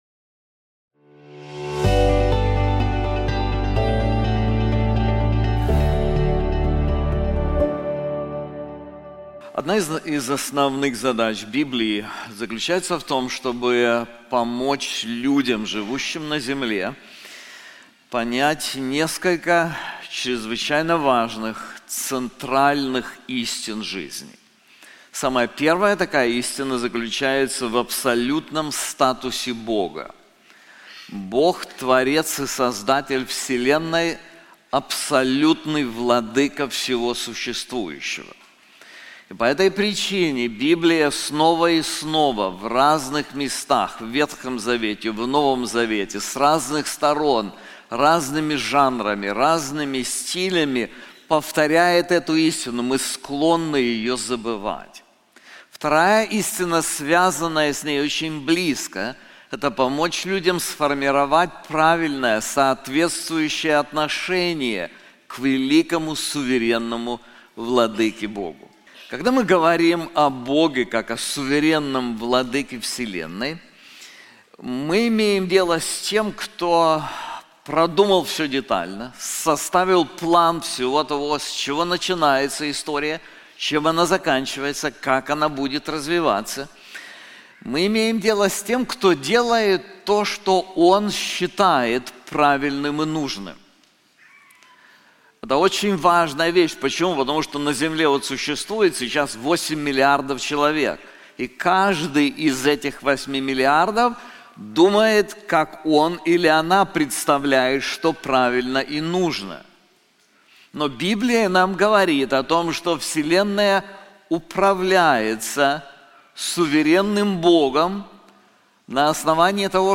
This sermon is also available in English:The Sovereign Savior • Isaiah 45:1-17